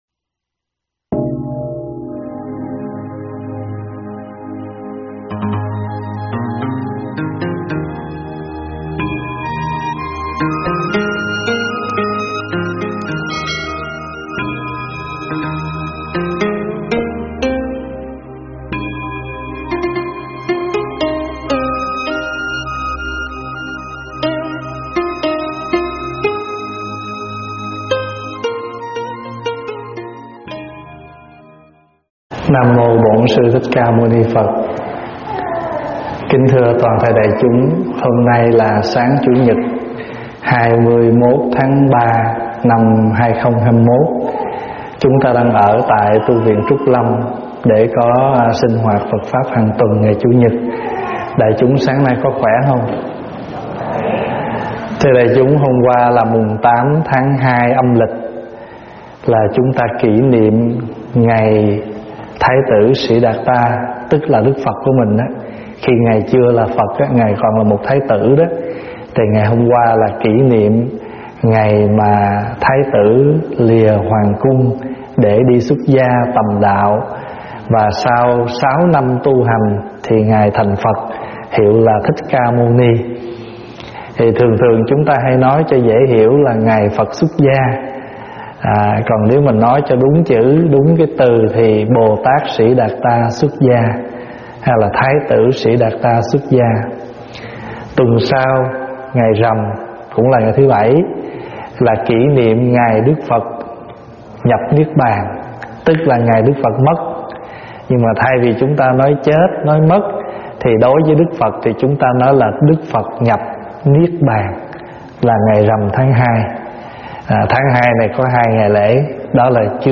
thuyết pháp Nơi Về Mãi Mãi
tại Tv. Trúc Lâm